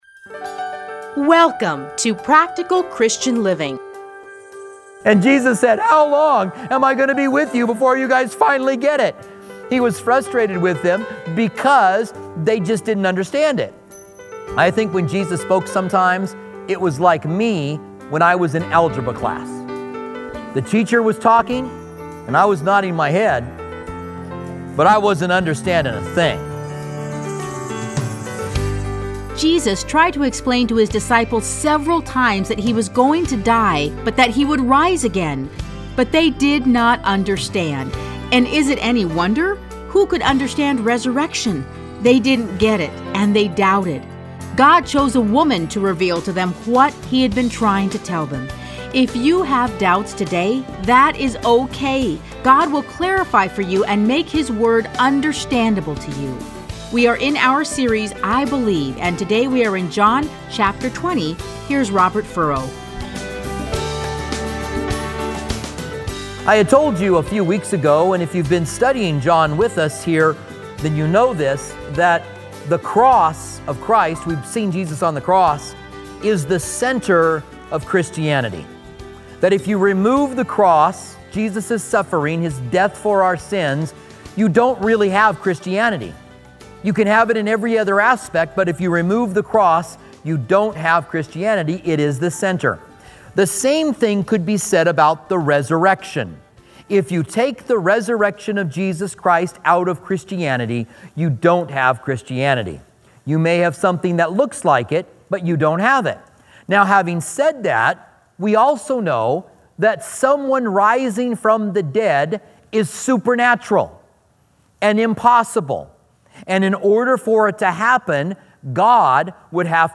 Listen to a teaching from John 20:1-18.